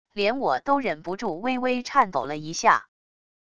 连我都忍不住微微颤抖了一下wav音频生成系统WAV Audio Player